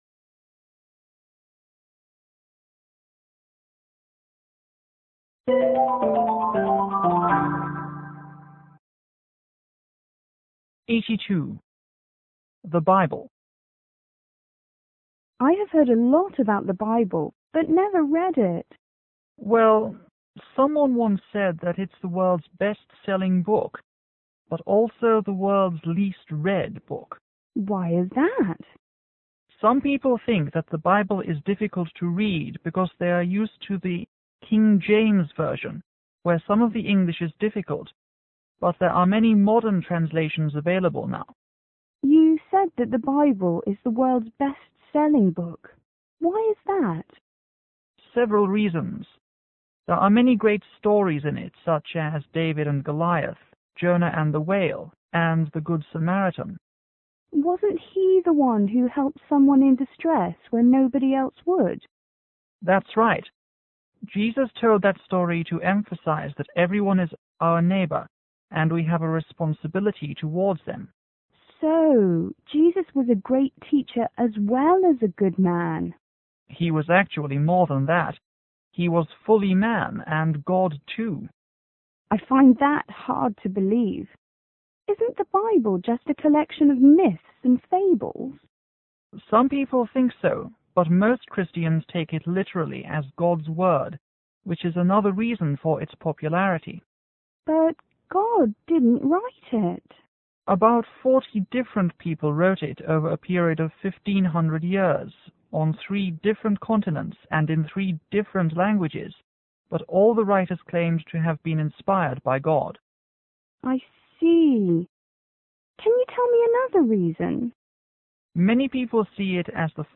C:Chinese student     F:Foreigner